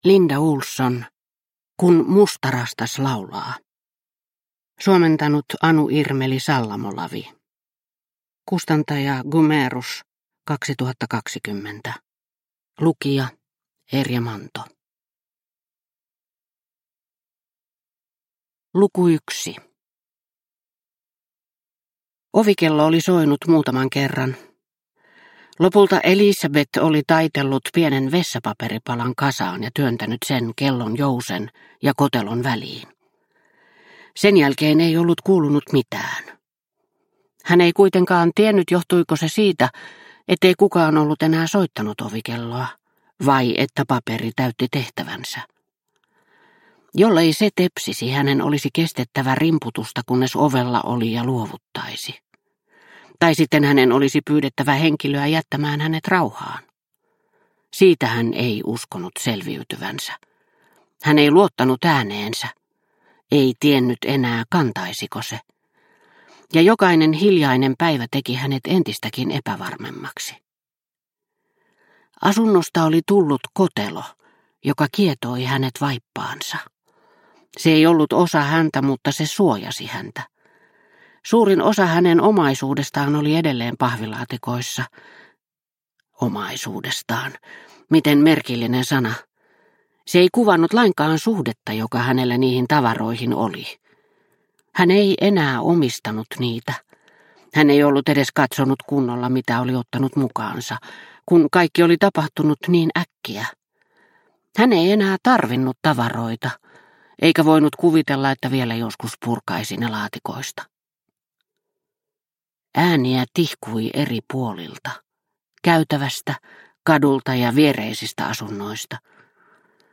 Kun mustarastas laulaa – Ljudbok – Laddas ner